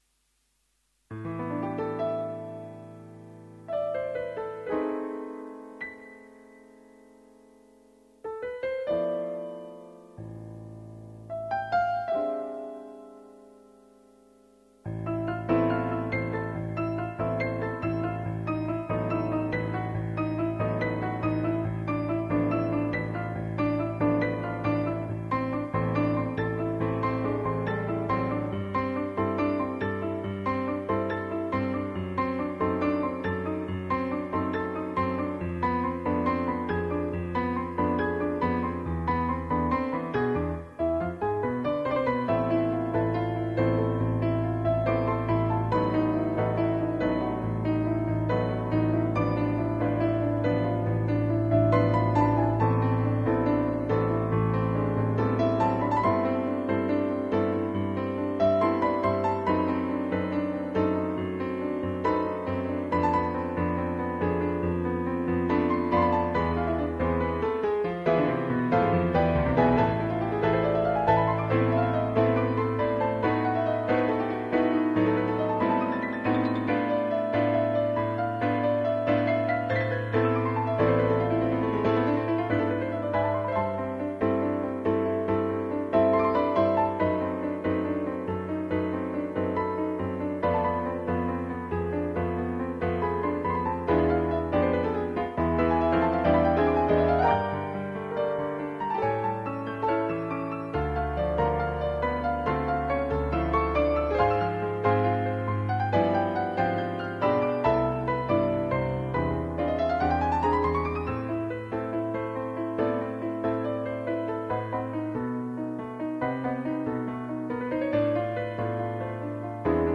アンサンブルでは、深みのあるチェロの音がよりずっしり響く名曲